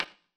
darkwatch/client/public/dice/sounds/surfaces/surface_wood_table7.mp3 at 646a16cd0f311f525a4cb0e9417e8f757a6c2d4d
surface_wood_table7.mp3